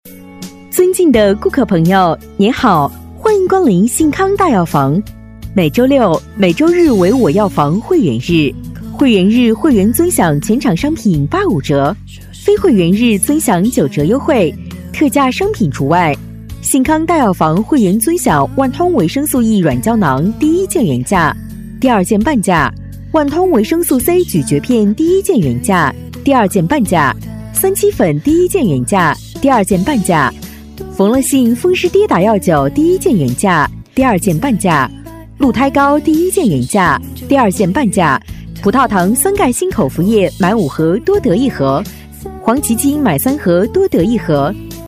女53-药店广播-幸康大药房-亲切成熟
女53-药店广播-幸康大药房-亲切成熟.mp3